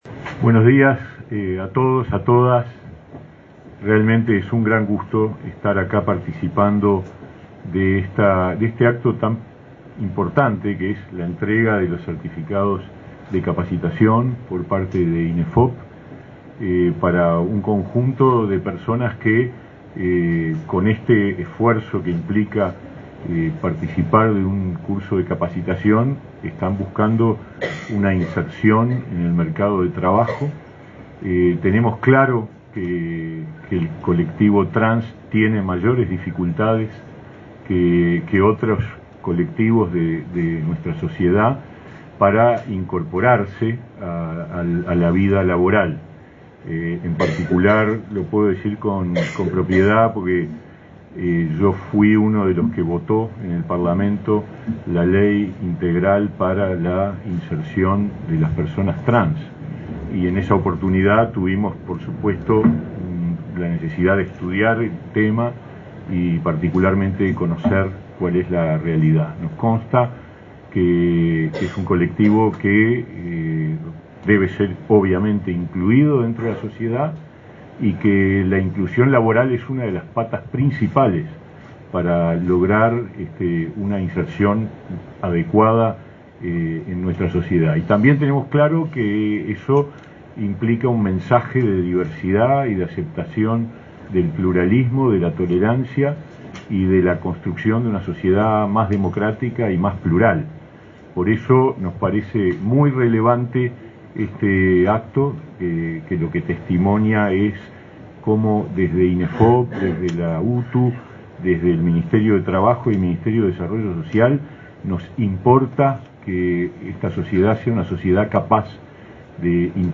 Palabras del ministro de Trabajo, Pablo Mieres
El ministro de Trabajo y Seguridad Social, Pablo Mieres, participó, este viernes 24 en la sede del Instituto Nacional de Empleo y Formación